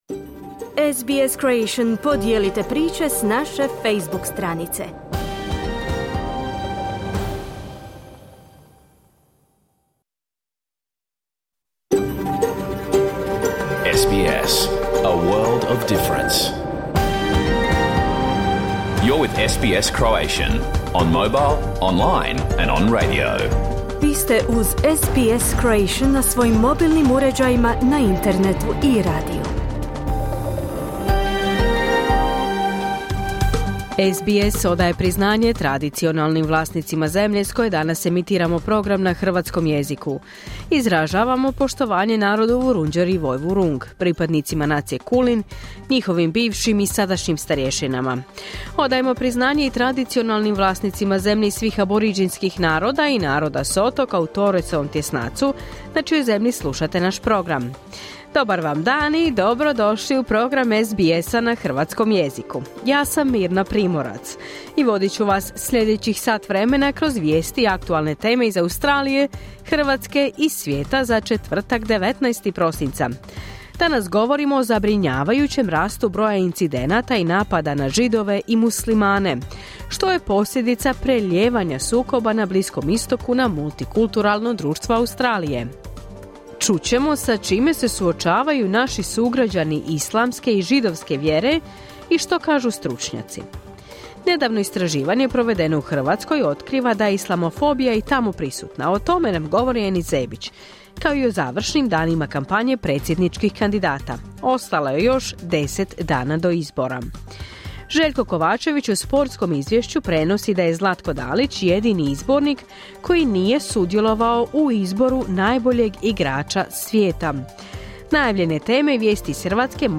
Vijesti i aktualnosti iz Australije, Hrvatske i svijeta. Emitirano na radiju SBS1 u 11 sati po istočnoaustralskom vremenu.